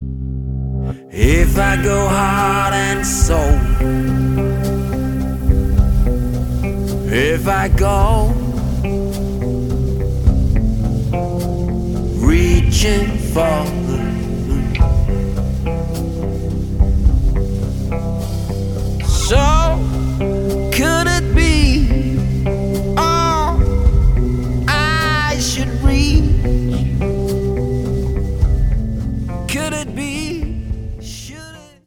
• Americana
• Blues
• Indie
• Rock
Vokal
Trommer